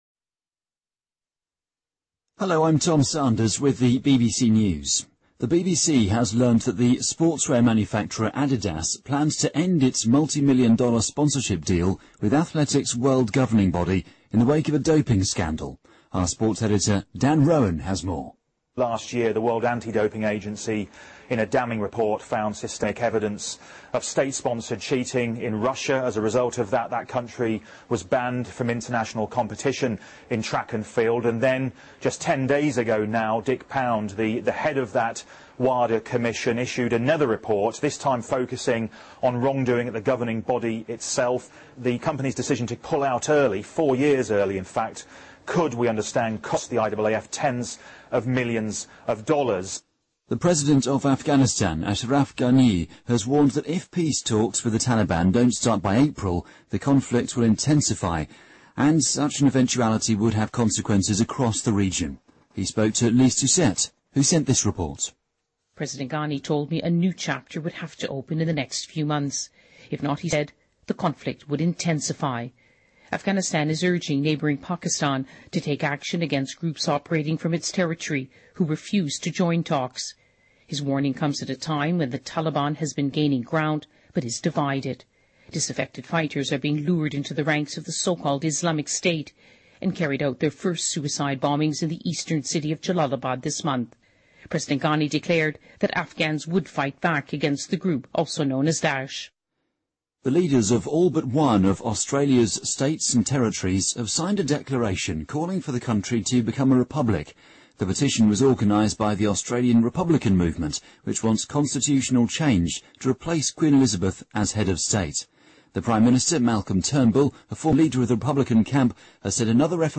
日期:2016-01-28来源:BBC新闻听力 编辑:给力英语BBC频道